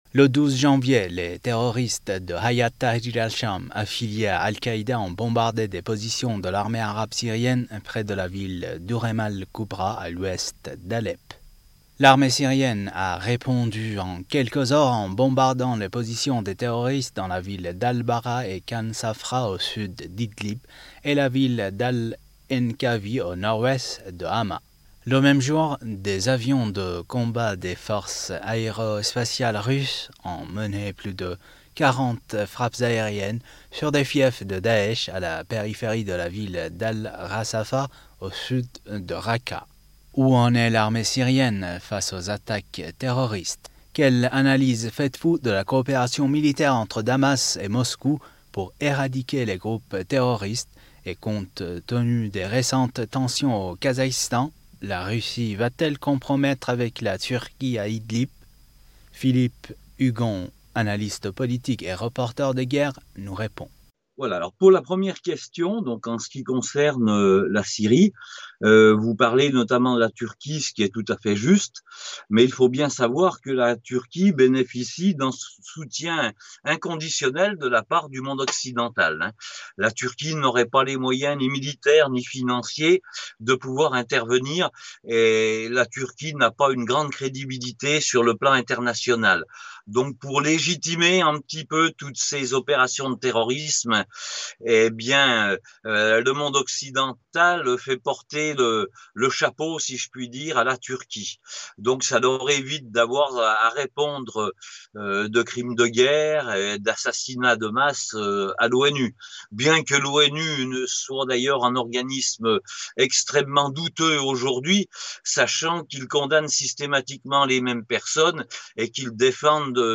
» Mots clés Syrie Russie interview Eléments connexes Rôle déterminant des femmes iraniennes dans l’industrie de défense A